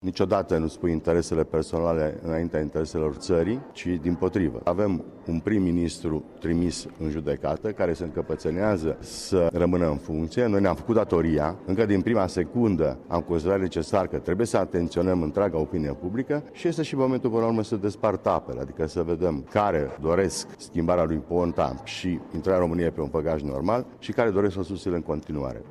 Co-președintele liberal, Vasile Blaga, a afirmat cã parlamentarii coaliției guvernamentale au acum prilejul sã aleagã, spune el, între binele public și egoismul politic de a rãmâne la putere :
blaga_motiune.mp3